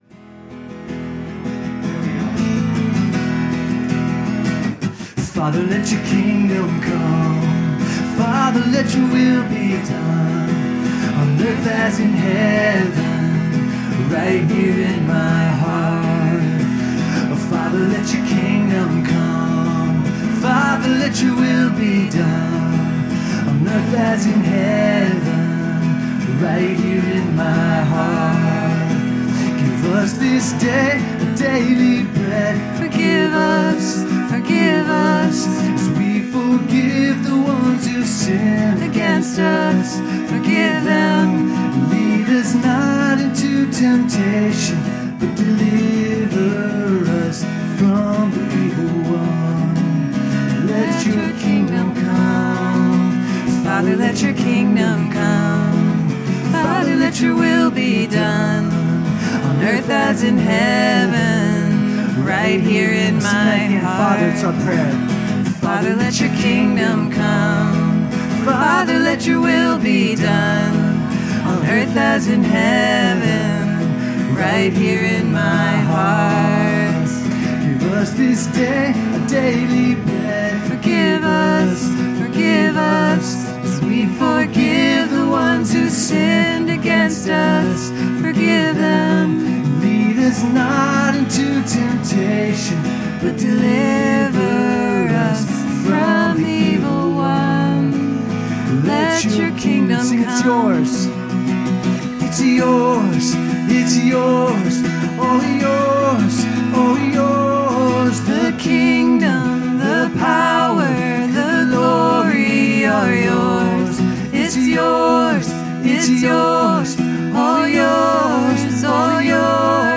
Worship 2025-02-23